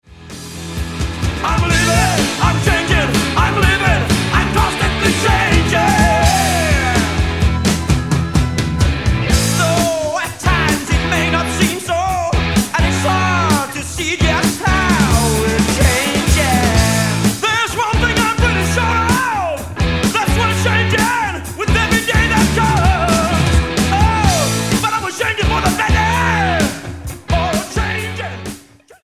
STYLE: Hard Music
the third album from Sweden's rock/metal band
riff-heavy